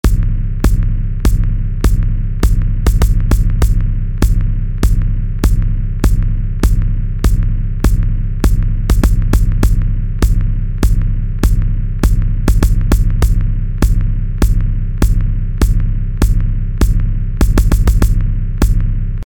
Zusammen mit diesen Einstellungen für Click, Top und Tools …
… klingt die TR 808 – Bassdrum jetzt so: